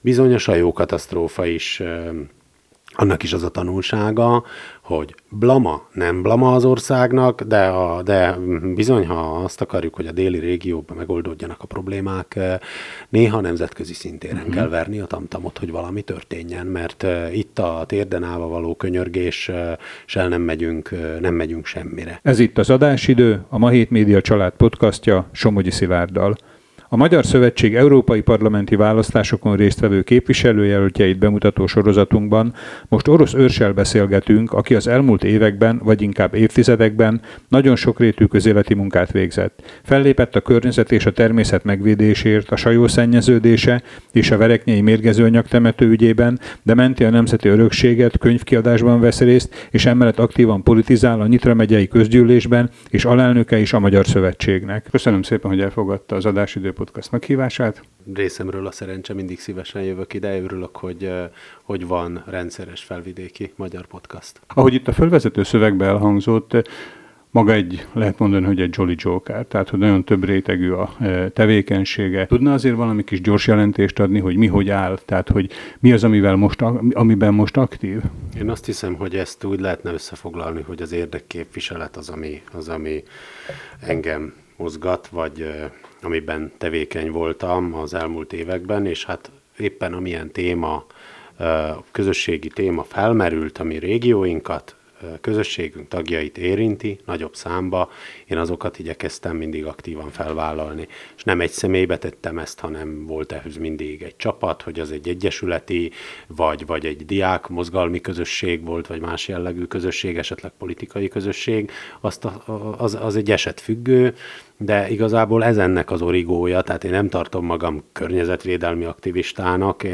az Adásidő stúdiójában